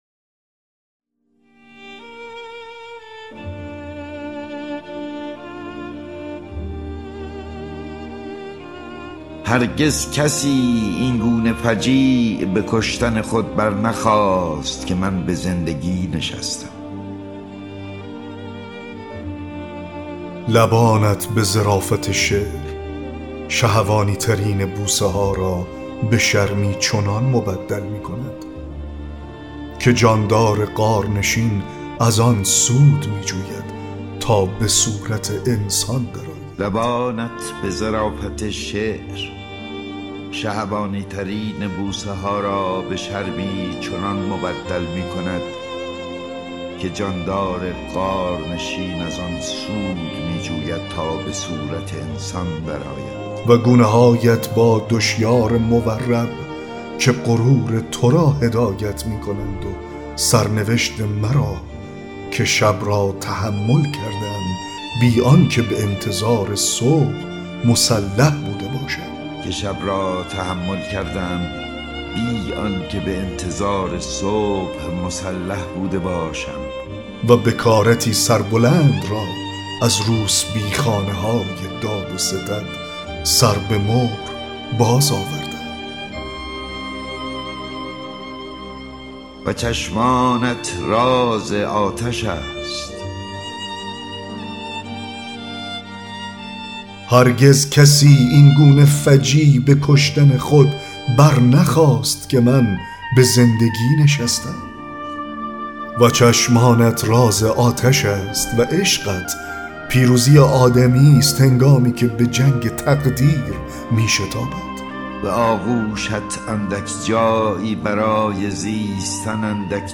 9- دکلمه شعر آیدا در آینه (لبانت به ظرافت شعر…)